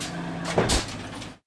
machine_attack2.wav